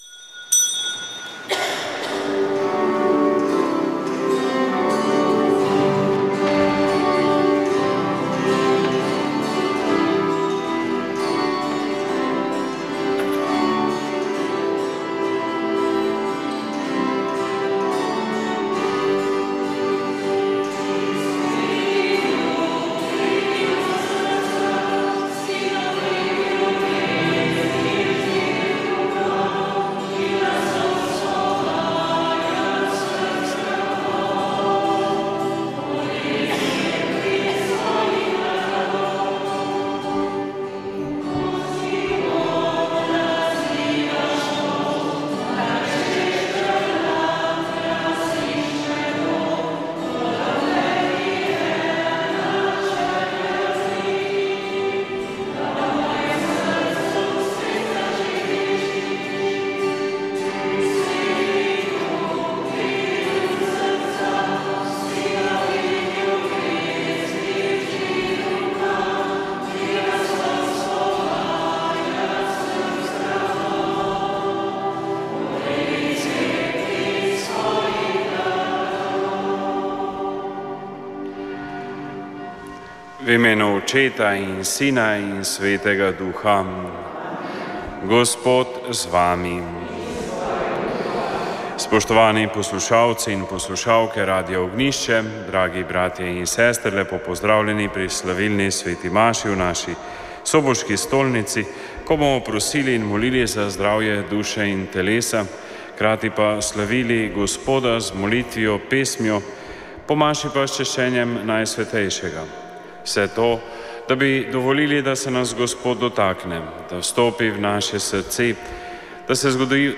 Sv. maša iz cerkve Marijinega oznanjenja na Tromostovju v Ljubljani 6. 10.
Pela je skupina Prenova v duhu - Jezusovega in Marijinega srca - Marijino oznanjenje.